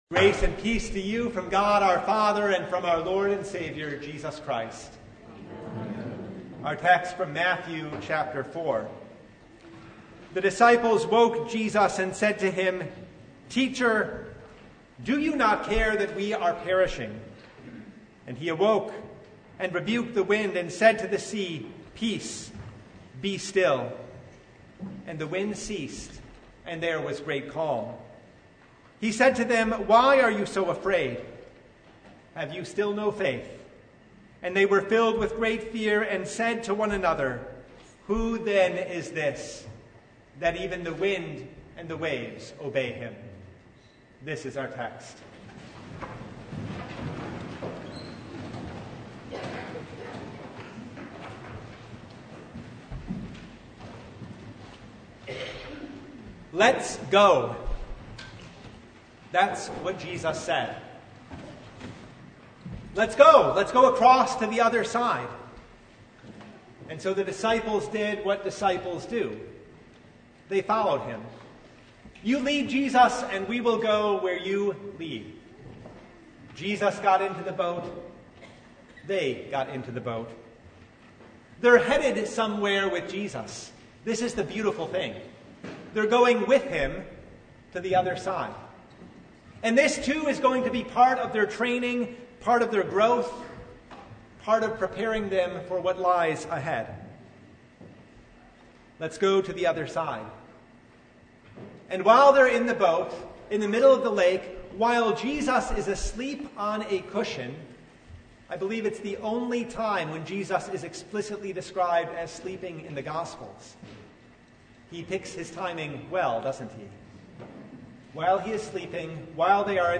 Mark 4:35-41 Service Type: Sunday Jesus said